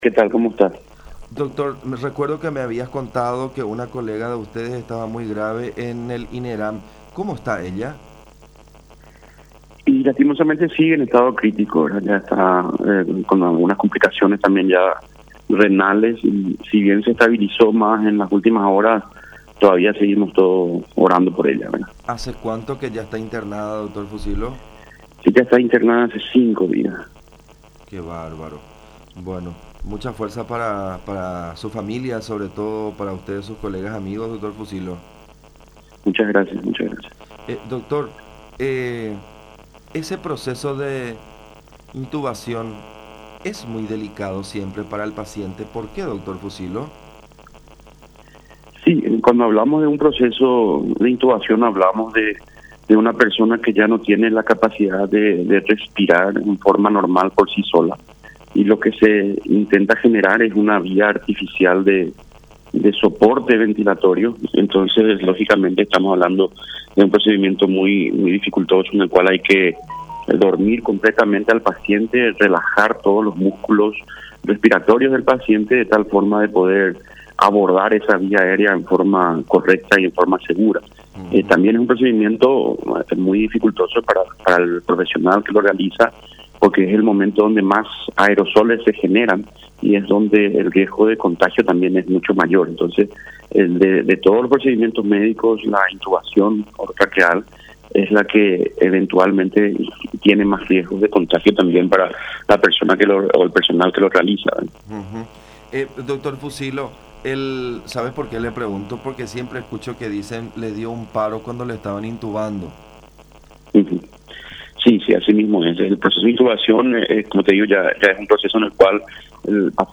en conversación con La Unión